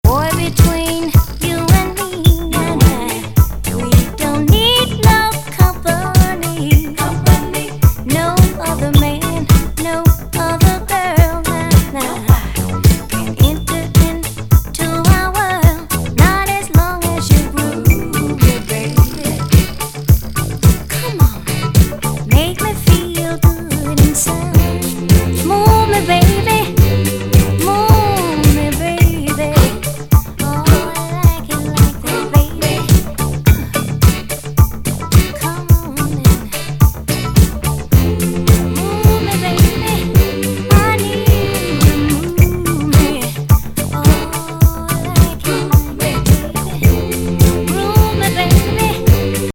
南部産フィメール・モダン・ソウル～ディスコな79年作。